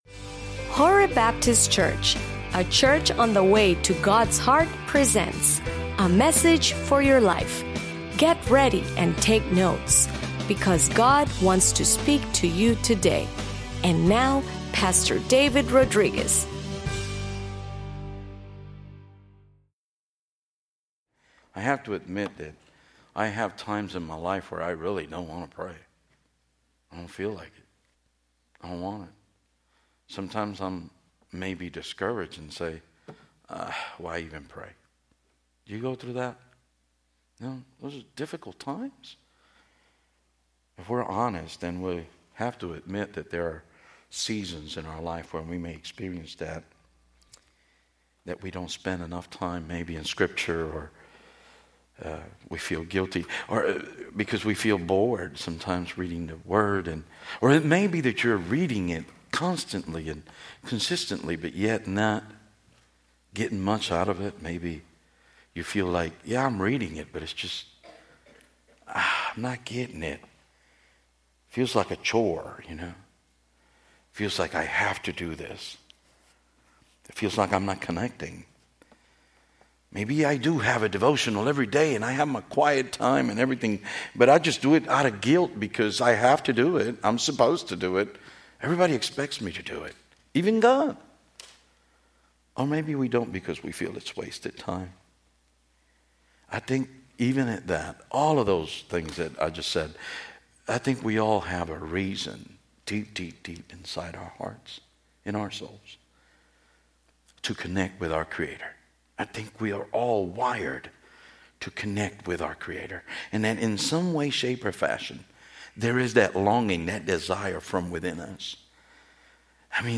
Sermons Archive - Page 34 of 45 - HOREBNOLA